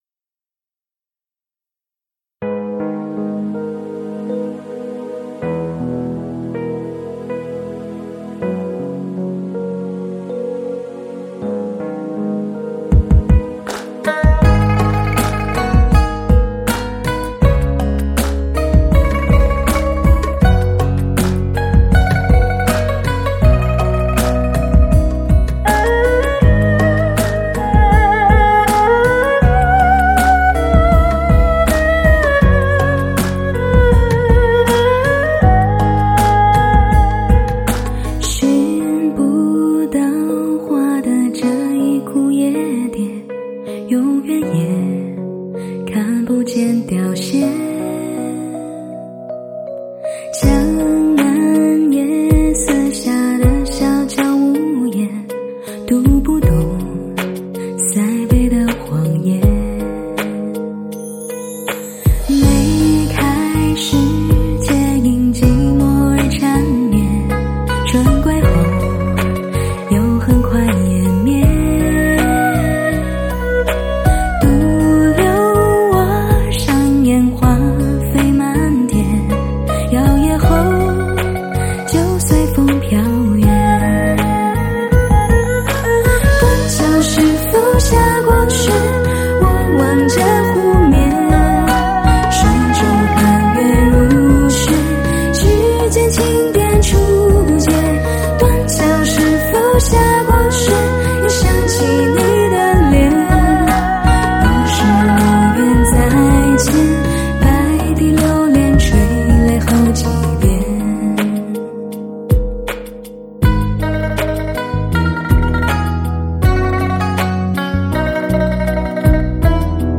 听女人唱情歌 给女人的听的HIFI大碟
这是一辑唱给女人听的HIFI音乐大碟，一辑声色细腻醇美的流行女声靓碟。
极富视听效果的发烧靓声德国版HD高密度24bit数码录音。